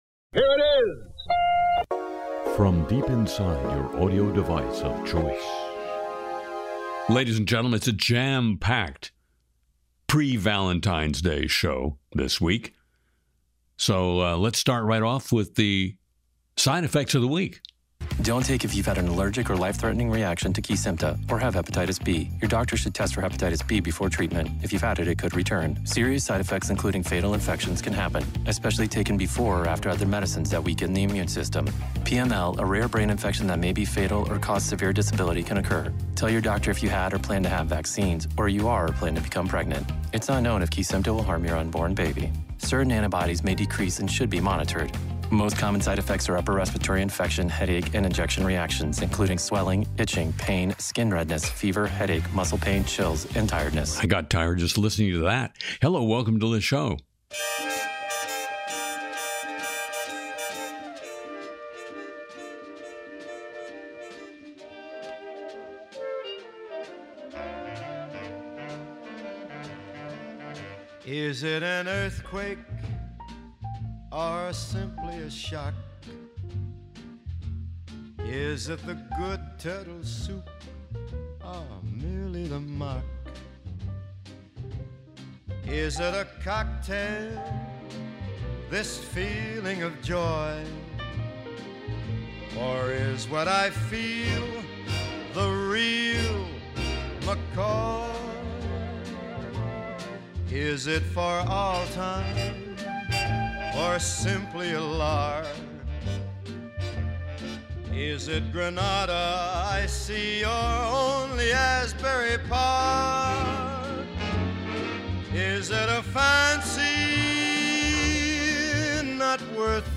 Harry and the Stephen Miller Band sing “Quota Cowboy,” dig into Epstein Files chaos, spoof media decline with “Nixon in Heaven,” track ICE leadership changes, and flag AI disinformation.